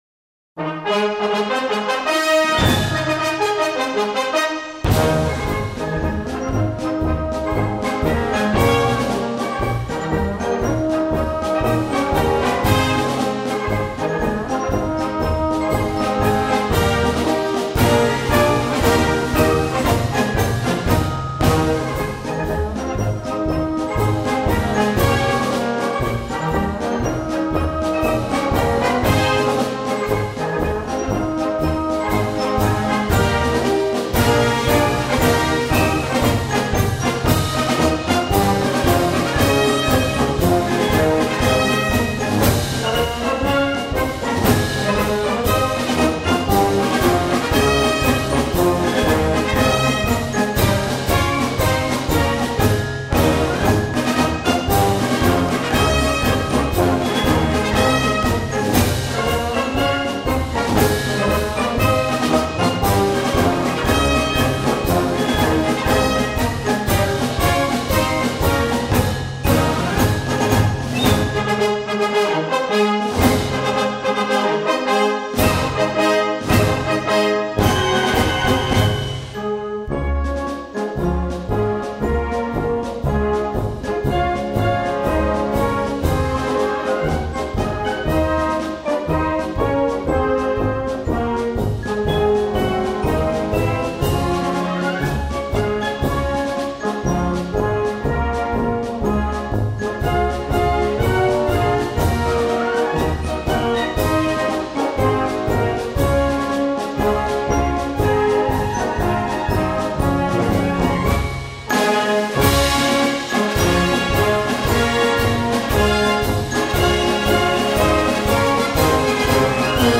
Rainer-Marsch downloaden (Trio gesungen) (mp3 | 02:30 min | 1,03 MB | Rechtsklick, Ziel speichern unter...)